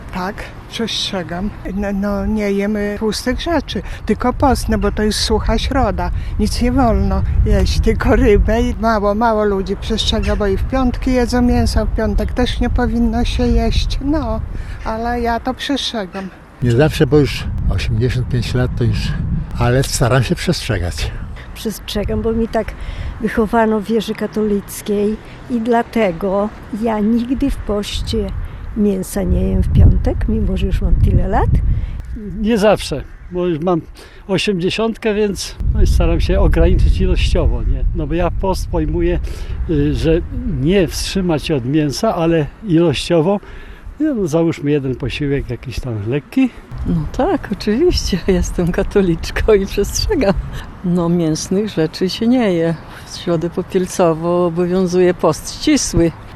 Na ulicach Suwałk zapytaliśmy, czy przechodnie obchodzą Wielki Post. Większość zapytanych twierdzi, że tak, ale niektórzy mówią, że z powodu wieku nie zawsze udaje im się przestrzegać postnej diety.